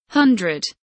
hundred kelimesinin anlamı, resimli anlatımı ve sesli okunuşu